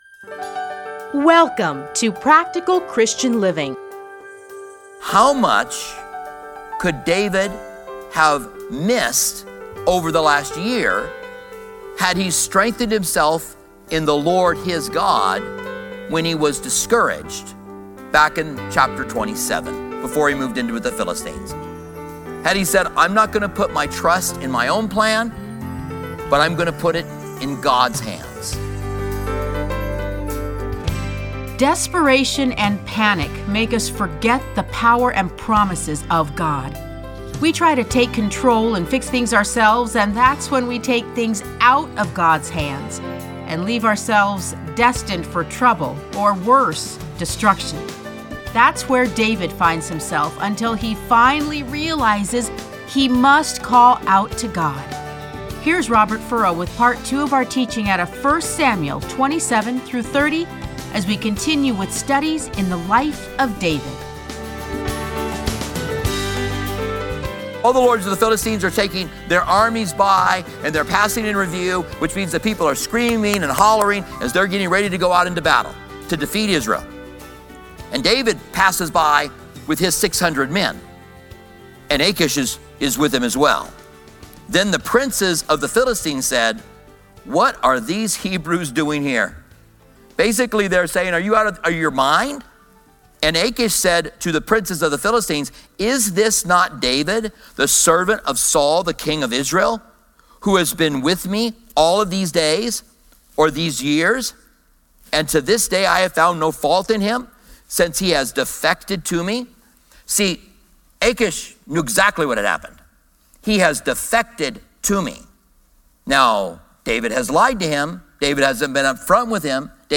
Listen to a teaching from 1 Samuel 27-30.